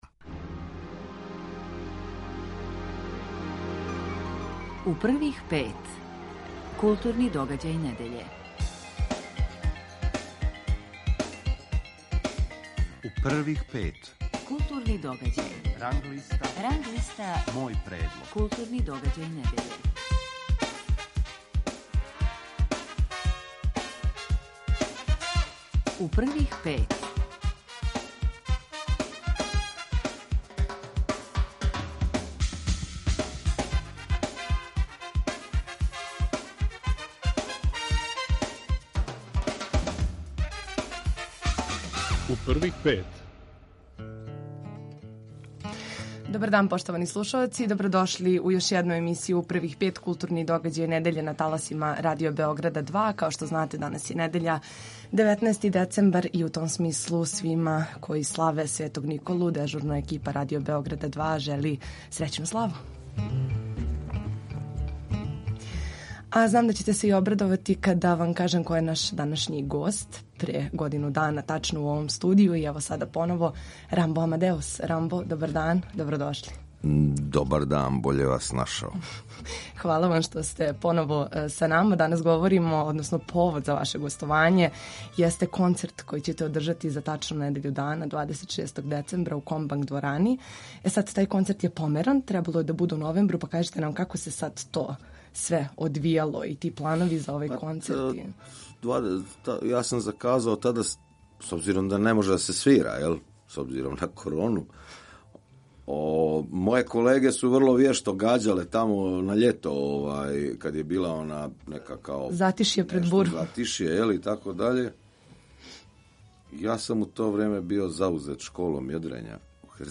Гост емисије је Рамбо Амадеус.